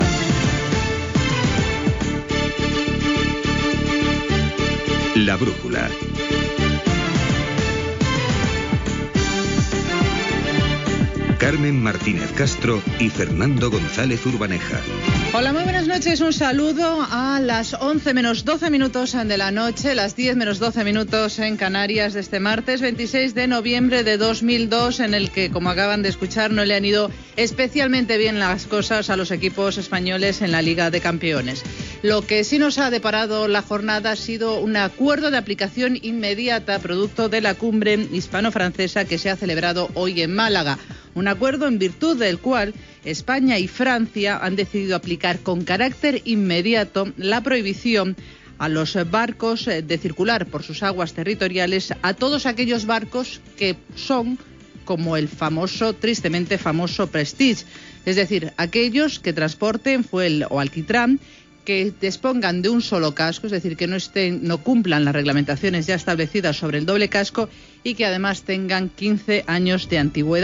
Careta i inici del programa, cimera hispano francesa a Màlaga.
Hora, presentació dels integrants de la tertúlia amb comentaris de futbol
Informatiu